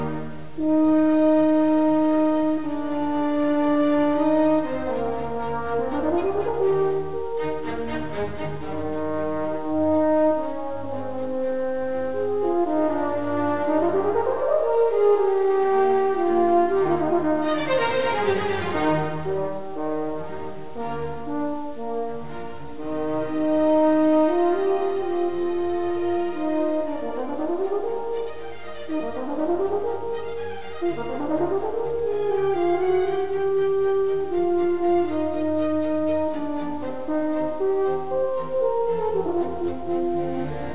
Audio clips of his playing